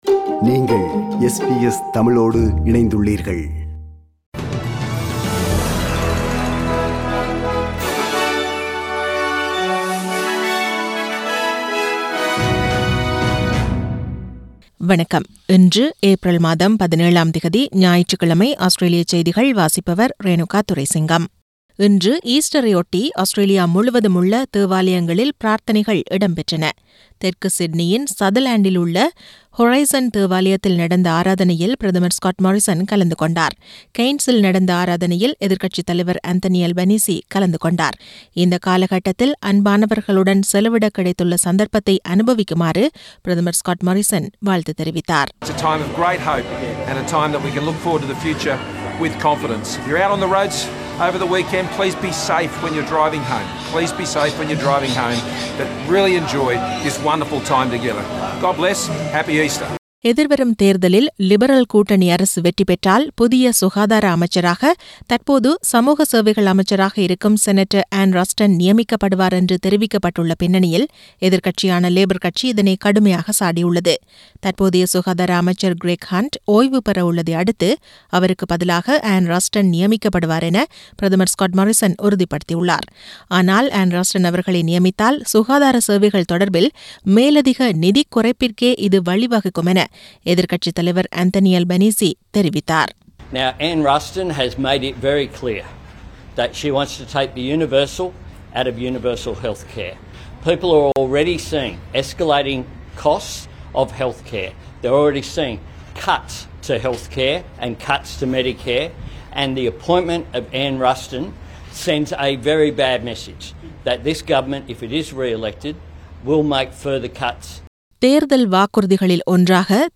Australian news bulletin for Sunday 17 Apr 2022.